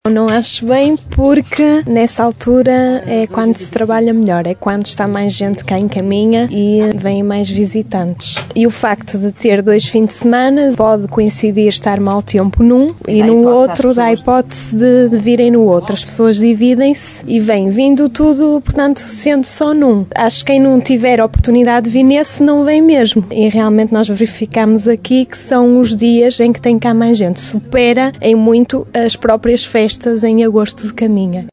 A Rádio Caminha saiu à rua para ouvir comerciantes e instituições do concelho e percebeu que as opiniões dividem-se, mas a maioria dos auscultados não concorda com a decisão do presidente da Câmara.